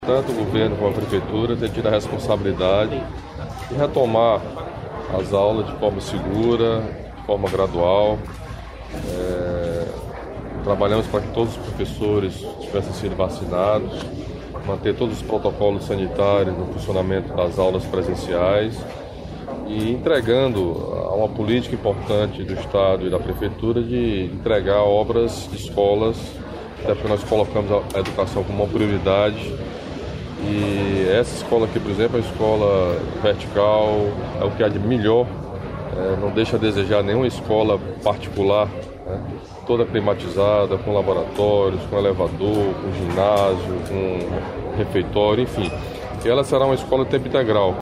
O governador Camilo Santana destacou a alegria em entregar a nova sede da Escola Eudoro Correia, agora mais uma escola em tempo integral.